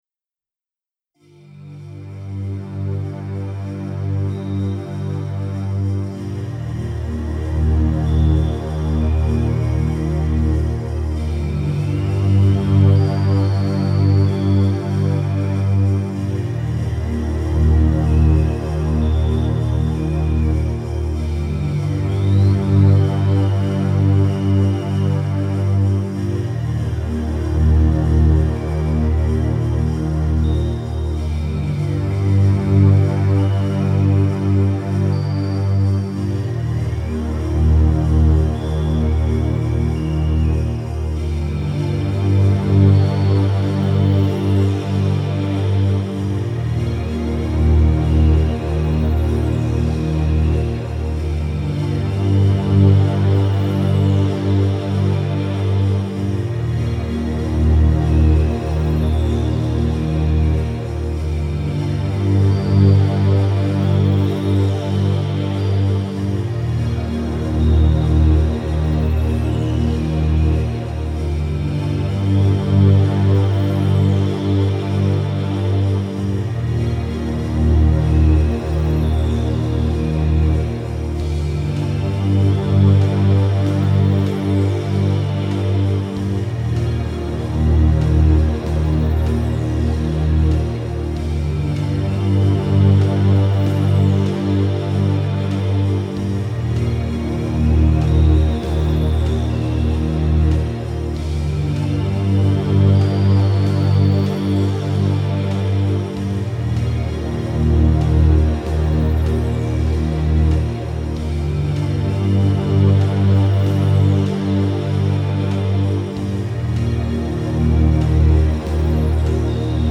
Achtergrondmuziek bij de Sway la Qi https